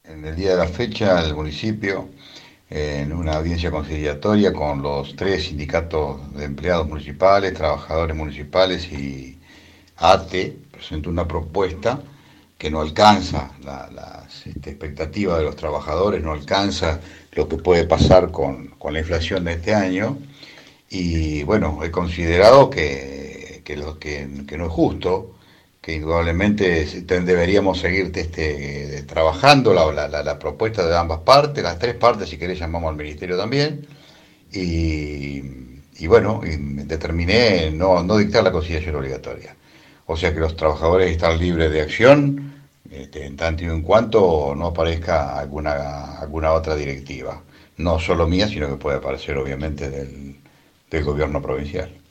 En declaraciones a FM Líder 97.7, el Delegado Regional, Oscar “Cachín” Rivada, explicó por qué no hizo lugar a la solicitud del Ejecutivo Municipal.